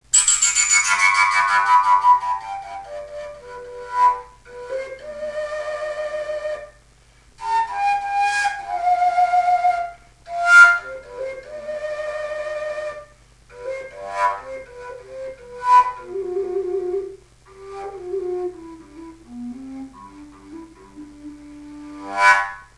Fujary vyrába v rôznych ladeniach.
Fujary a píšťaly